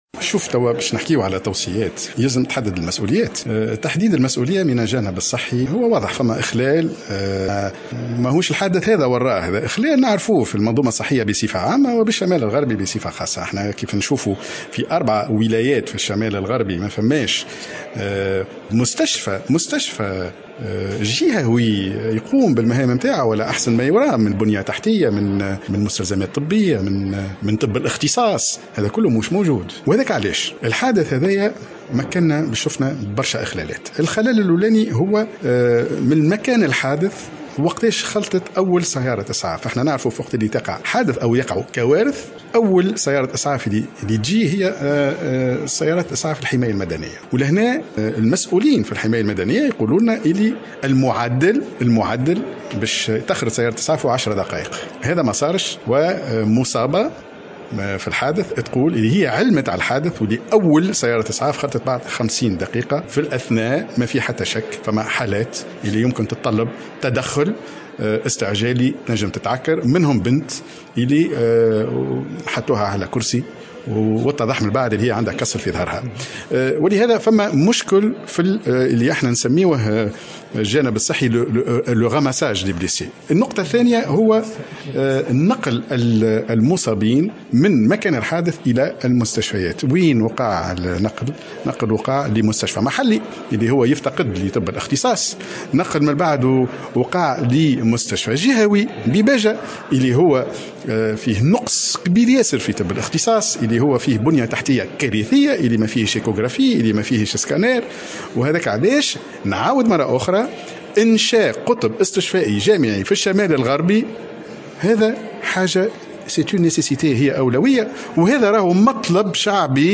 وأضاف النائب في تصريح للجوهرة أف أم اليوم الاثنين 08 جوان 2020، خلال لقاء إعلامي عقدته اللجنة لتقديم تقريرها النهائي، أن أعمال التحقيق أسفرت عن ملاحظة جملة من الإخلالات في علاقة بالحادث ومنها بالخصوص، التأخير في إسعاف المصابين، وهو ماحال دون إنقاذ أرواح بشرية، إضافة إلى غياب طبّ الاختصاص و نقص التجهيزات الطبيبة الضرورية وفق تأكيده.